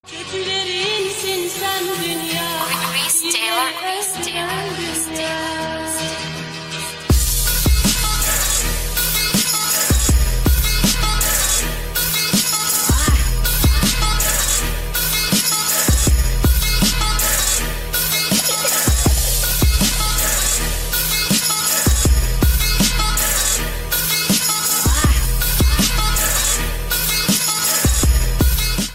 • Качество: 320, Stereo
женский голос
восточные
Трэп с нотками Востока)